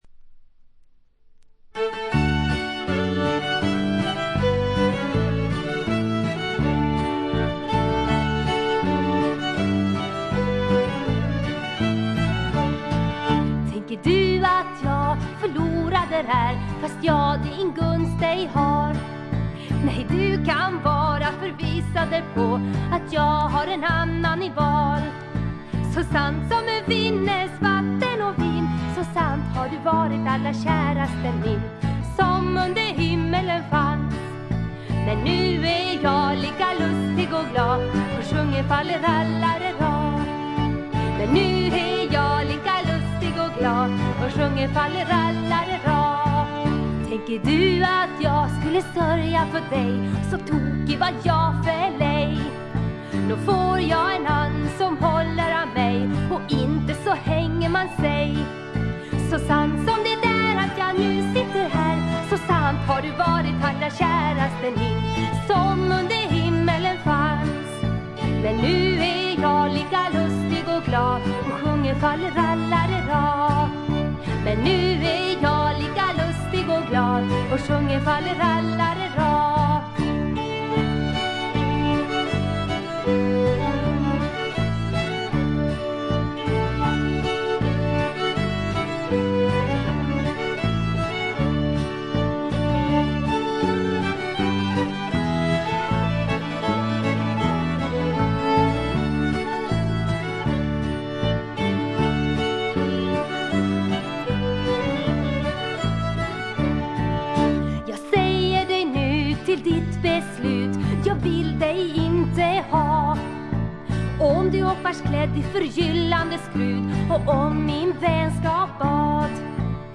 軽微なプツ音が2回ぐらい出たかな？という程度でほとんどノイズ感無し。
ちょっとポップなフォーク・ロックも素晴らしいし激渋のトラッドも良いです。
試聴曲は現品からの取り込み音源です。
Violin